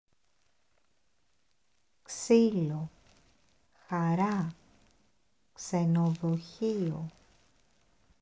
• χ = “kh” (son rauque)
• ξ = “ks” (comme taxi, fixe)
• ξύλο (bois) → [ksílo], pas khílo